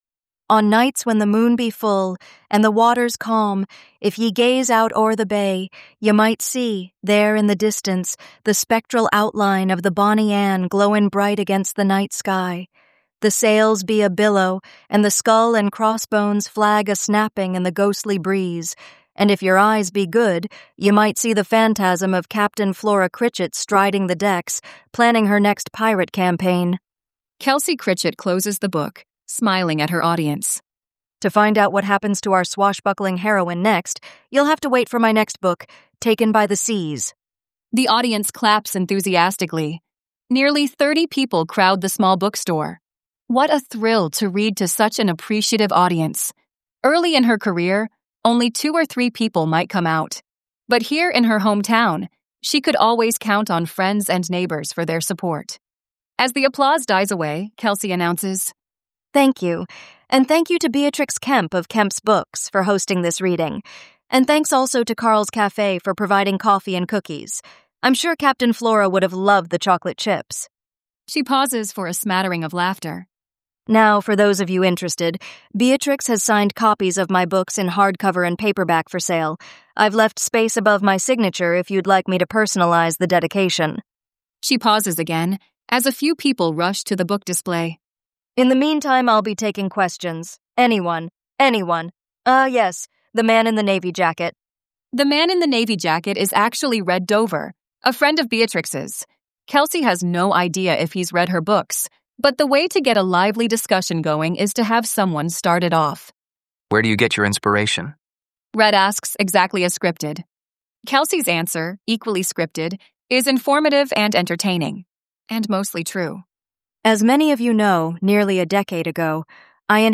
Ghost Writer is available as an audiobook .